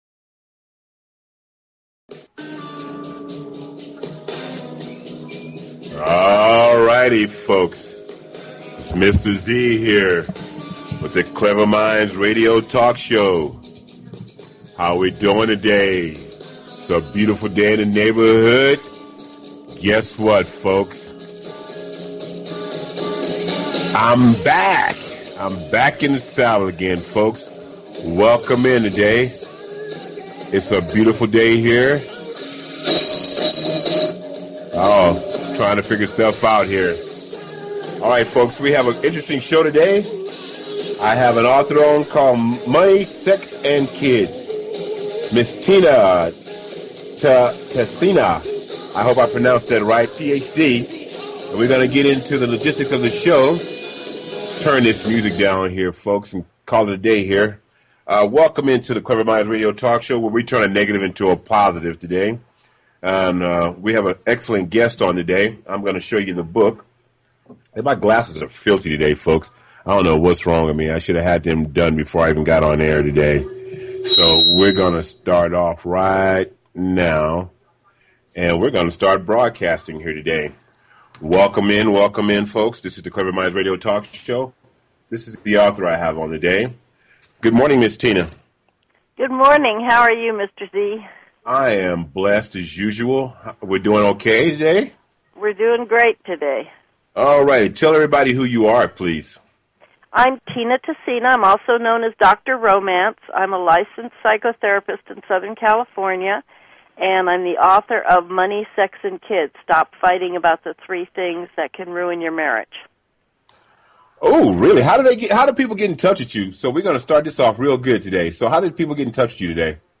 Talk Show Episode, Audio Podcast, Cleverminds and Courtesy of BBS Radio on , show guests , about , categorized as